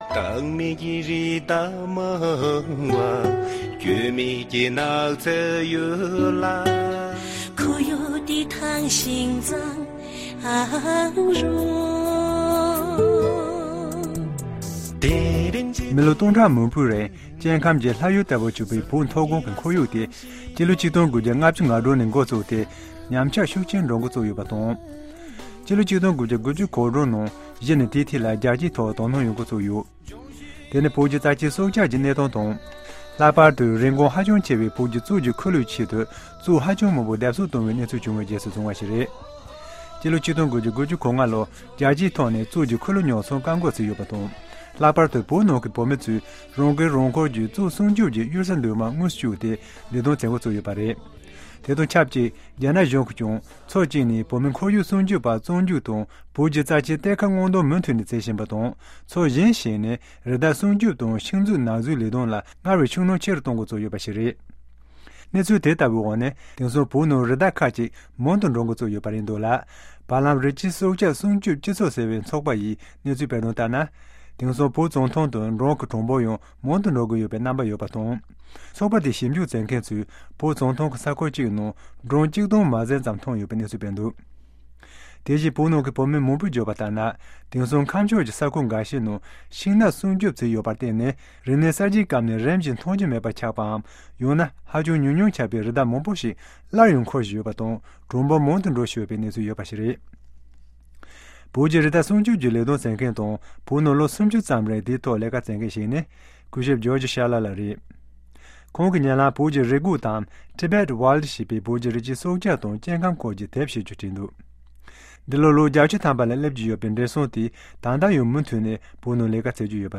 This is the result of conservation efforts made in the past two decades, which has a strong local participation.Conservationist George Schaller has spent 30 years in the Tibetan Plateau, working with local nomads to protect Tibetan wildlife. In this program Tibet in Review has exclusively interviewed the 80-year-old conservationist about the current wildlife situation on the plateau. He says that there are now about 150,000 Tibetan antelopes.